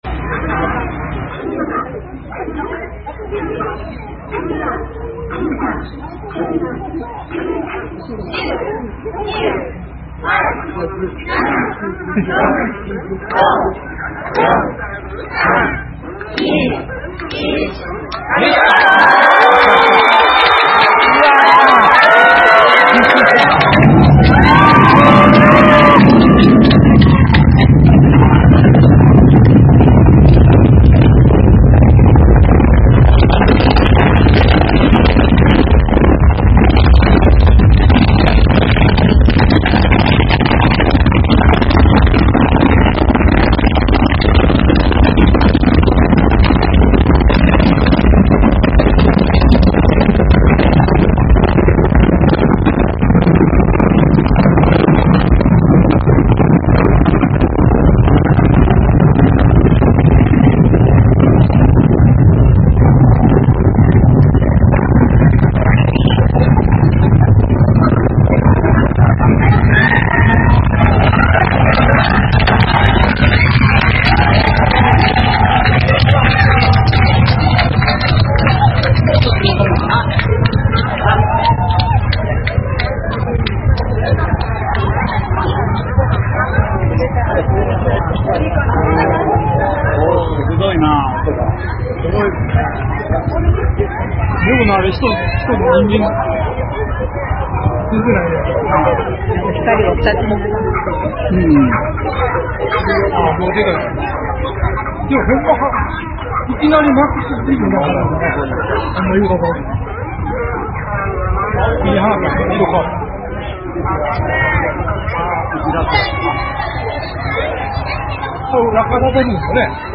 ほんで、去る１９日の話になりますが、超〜ラッキーな事に「こうのとり」の打ち上げに”ランデブー”する事が出来ました。愛用の携帯「らくらくホン」で映像も撮りましたよ。
みんなのカウントダウンに続き、暗闇にピカッと光る閃光の後から時間差でやってくるバリバリバリバリバリバリ・・・というものすごい発射音、そして観客の拍手を記録しています。
打ち上げの様子（音声のみ）
uchiage.mp3